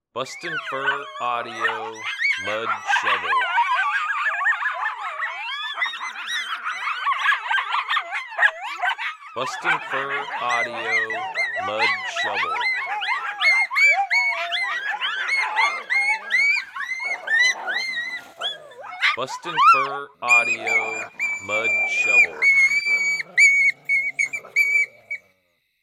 There is a lot of vocals packed into this 3 minute sound file, group chatters with growling, squalling mixed in and solo fighting as well. Definitely a sound to get the Coyotes fired up.
• Product Code: pups and fights